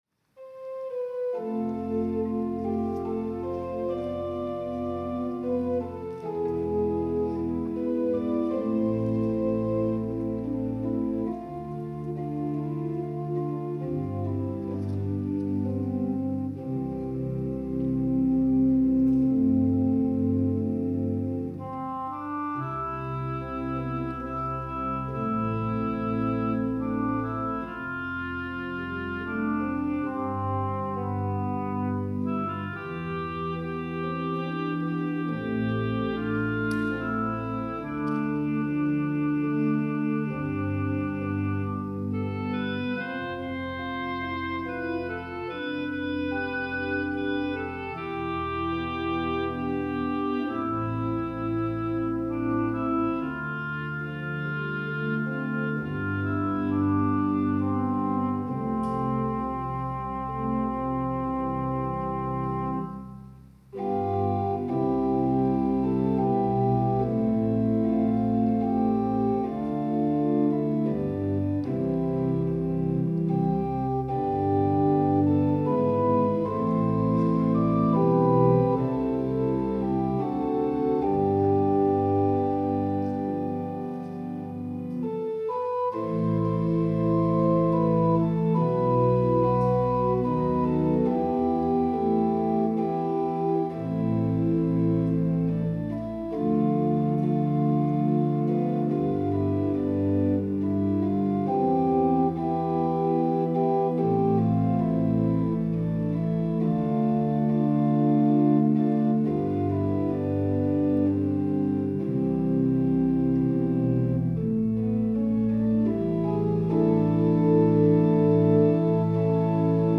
특송과 특주 - 주께 와 엎드려